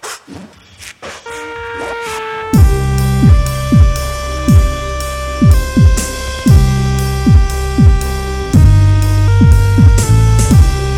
techno mix
sampled at 44.1kHz